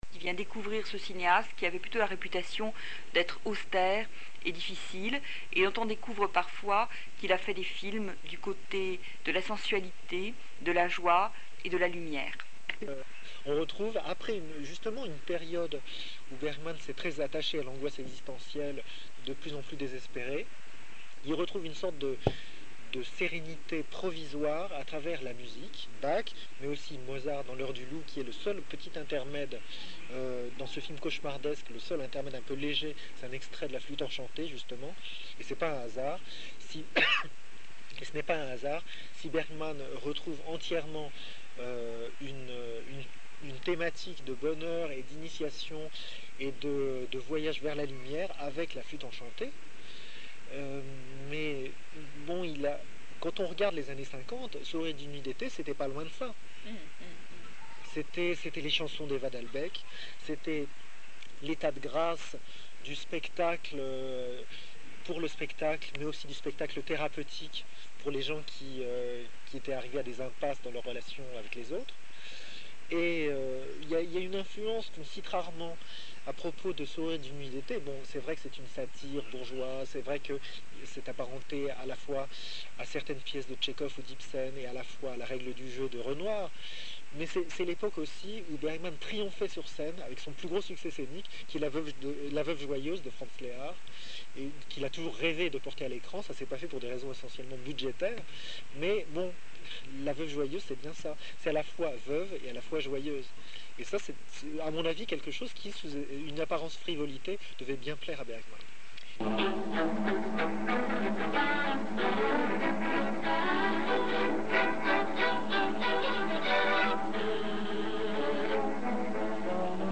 Vous entendrez plusieurs extraits courts de films de Bergman en langue originale qui m’ont paru très expressifs au plan sonore - rires, musiques, dialogues - et du livre Laterna magica , souvenirs et réflexions du cinéaste sur sa vie et sur son métier, parus en 1987 chez Gallimard.